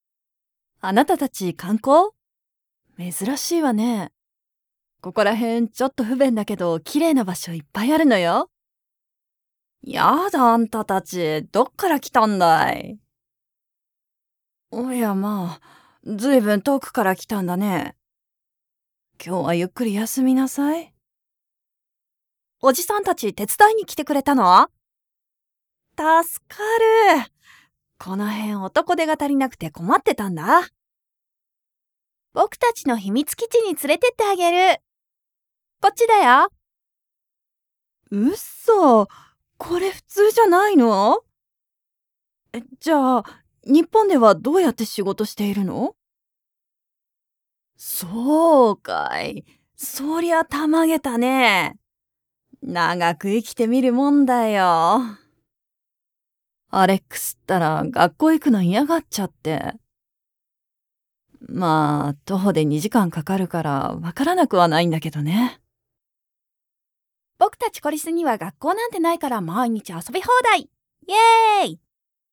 For those of you who want REAL Female JAPANESE voice over! Friendly, sweet, softspoken, believable, caring, warm and comfortable natural voice.
Sprechprobe: Sonstiges (Muttersprache):
Her voice can be natural, warm, friendly, inviting, yet approachable, suitable, sweet, playful, institutional and much more …!
Acting-7charactors.mp3